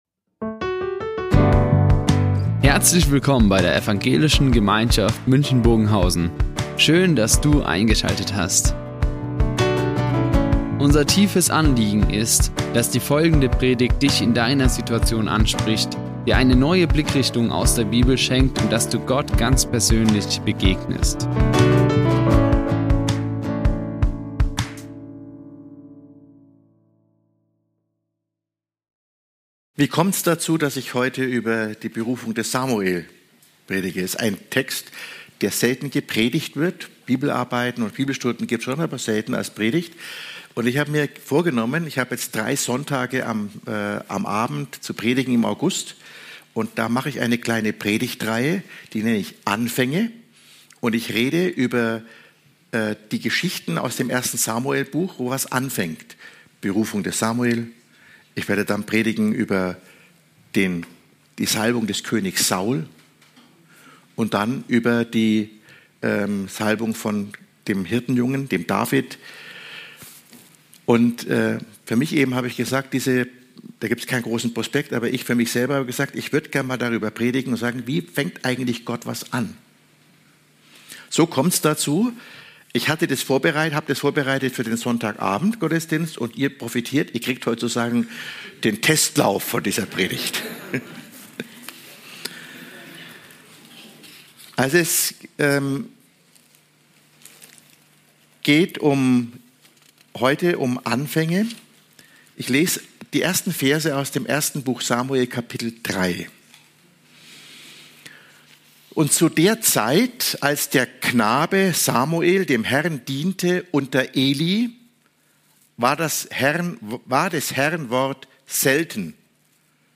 Samuels Berufung | Predigt 1. Samuel 3,1-10 ~ Ev.
Die Aufzeichnung erfolgte im Rahmen eines Livestreams.